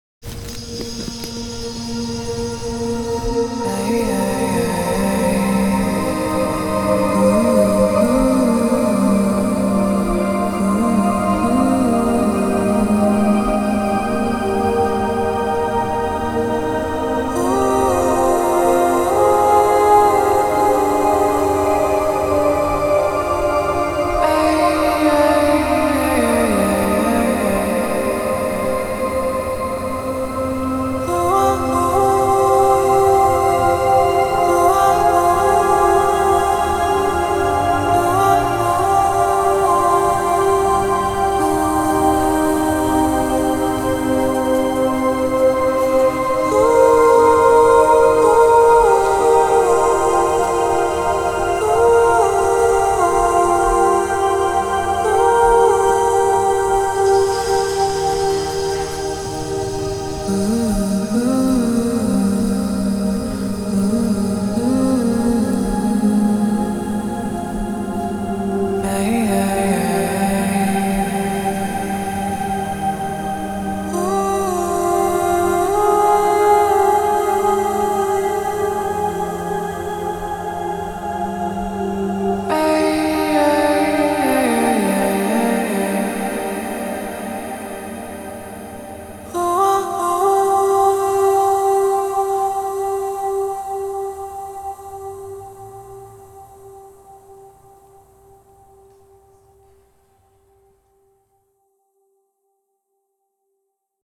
Home > Music > Ambient > Mysterious > Dramatic > Relaxation